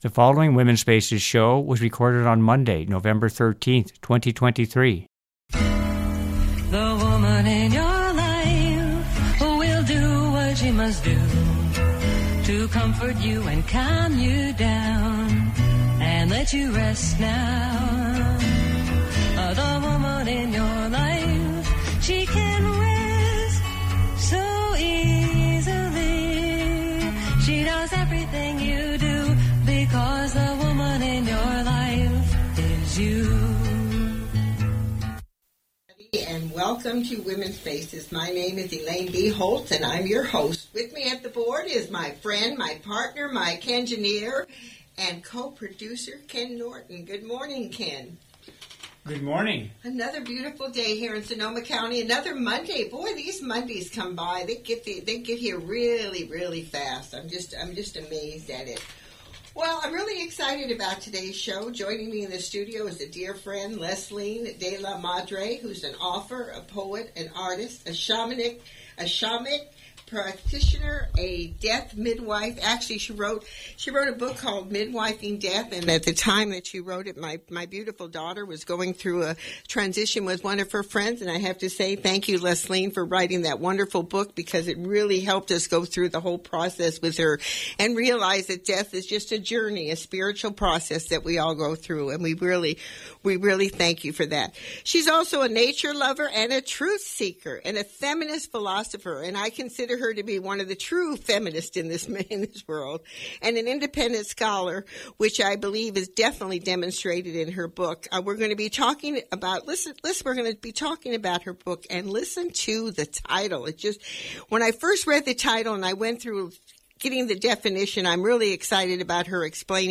Download the show Duration 57 minutes The Guest interview begins at 23 minutes.